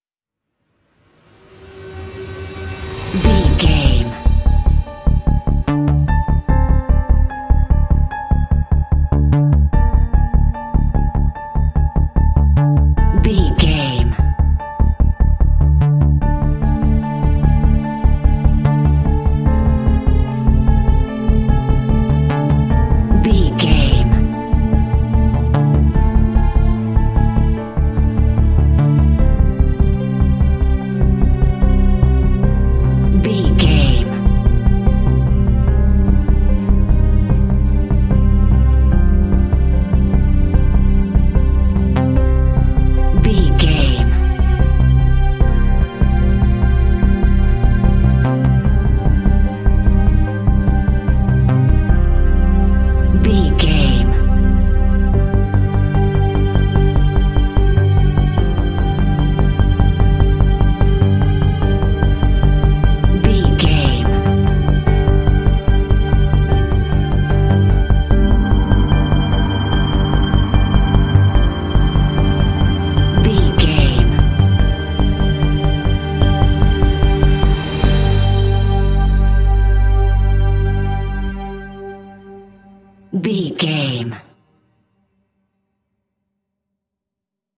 Aeolian/Minor
scary
suspense
foreboding
synthesiser
drums
strings
piano
cinematic
contemporary underscore